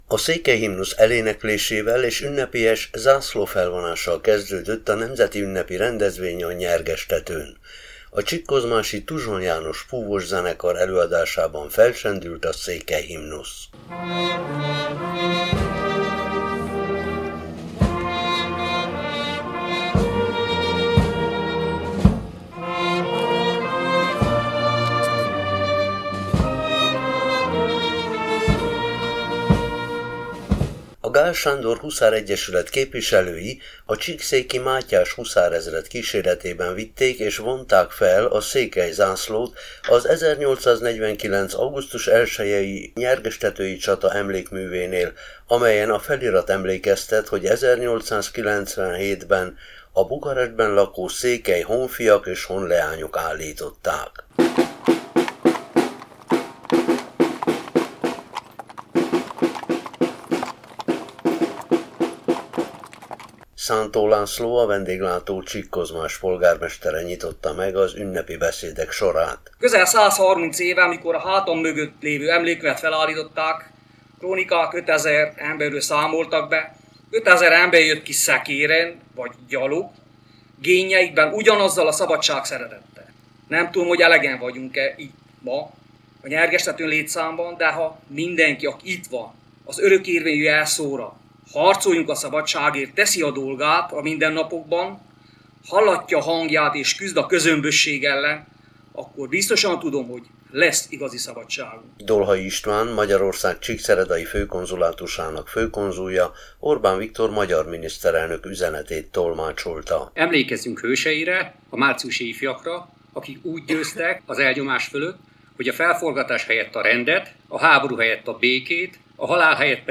Csíkszék és Kászonszék lakossága, Hargita Megye Tanácsa, Magyarország kormánya, csíkszeredai Főkonzulátusa, csíkszéki és kászonszéki önkormányzatok, ifjúsági és civil szervezetek képviselői, magyarországi és hazai meghívottak vettek részt március 15-én délután a Csíki medencét a Kászoni medencétől elválasztó Nyerges-tetőn tartott ünnepségen.